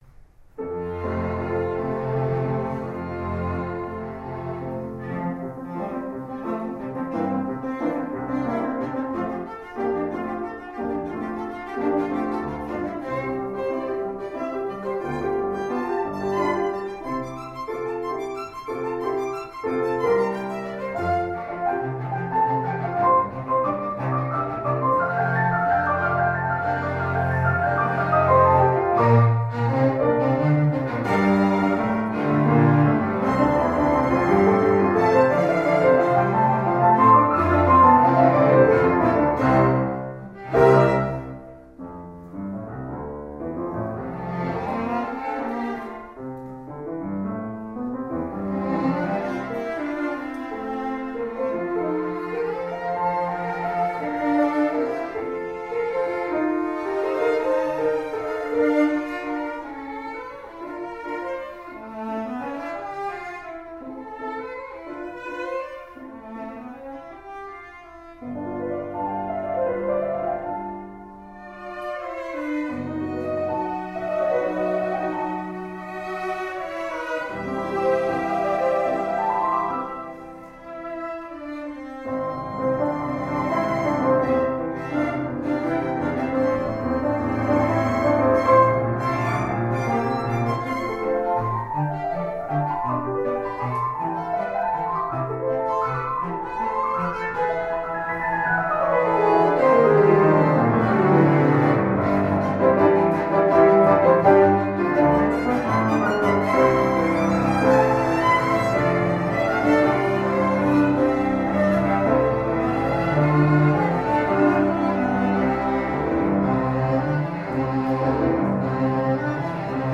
Piano Trio No.1, Op.18
cello
piano
Style: Classical
violin